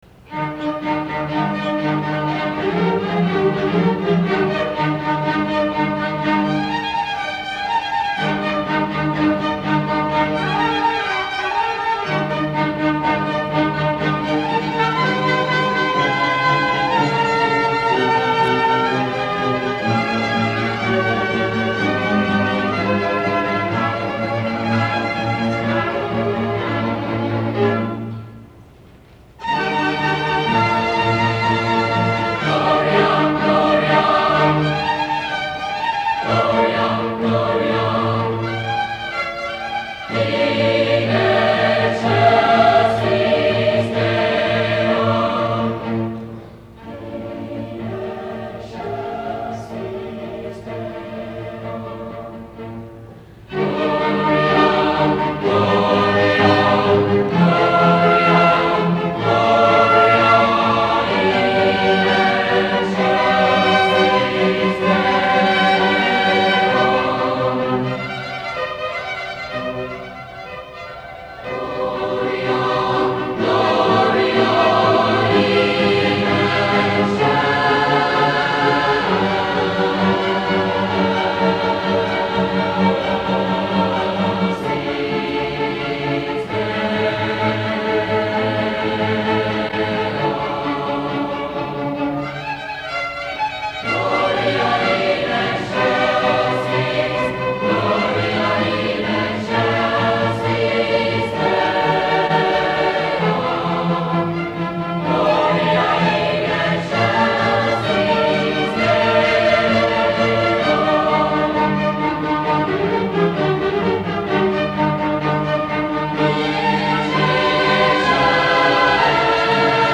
Christmas Concert 1972
Clay High Gym